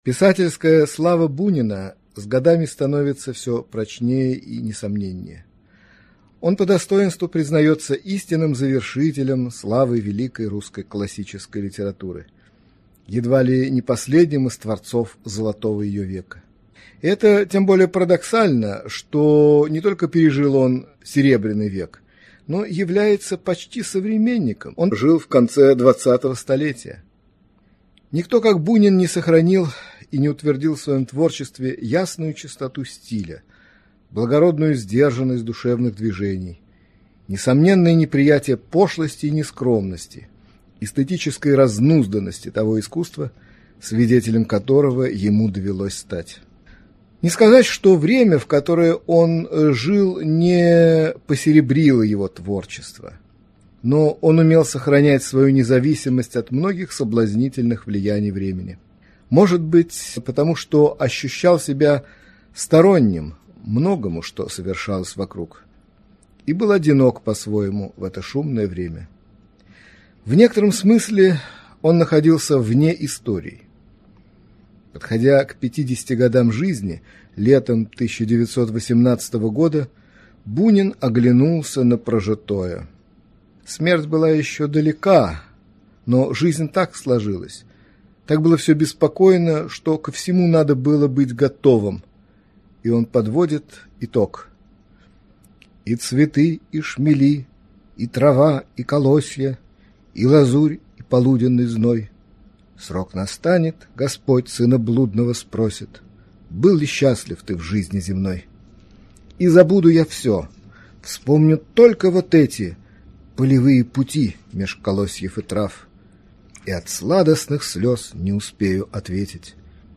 Аудиокнига Лекция